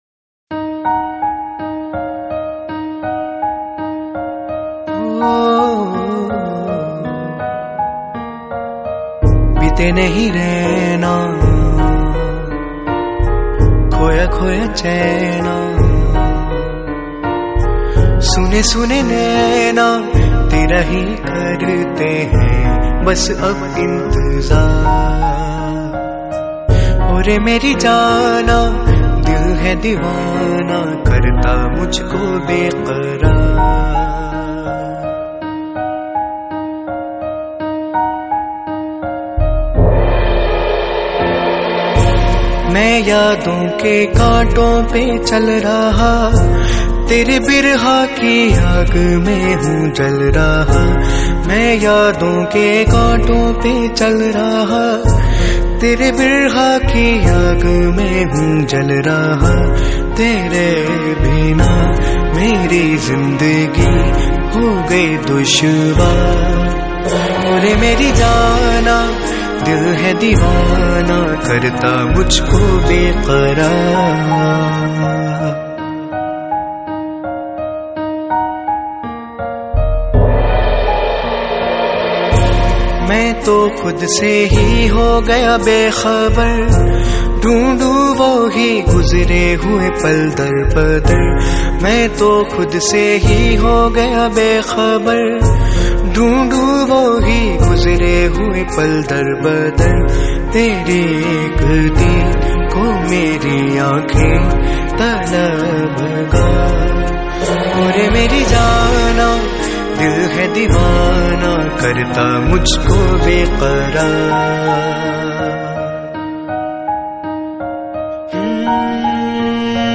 Bollywood Mp3 Music 2018